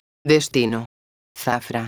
megafonias exteriores
destino_zafra.wav